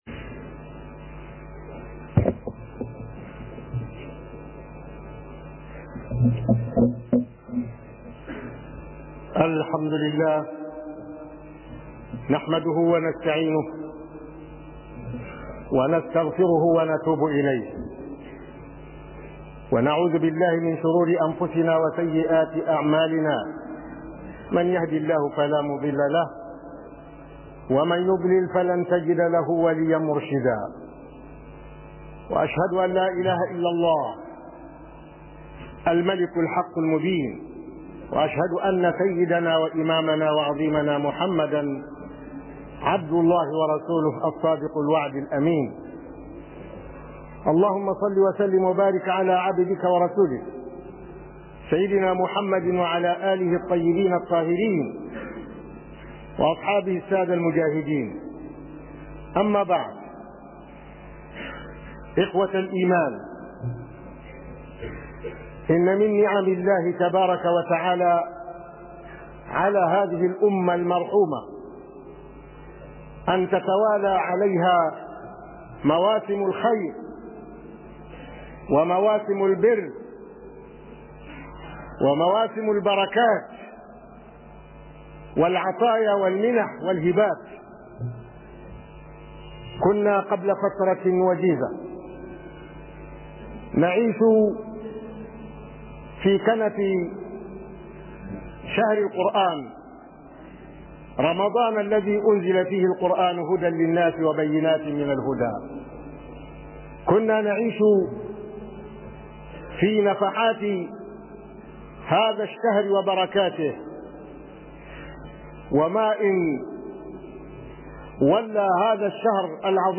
KHUDBAH JUMA-A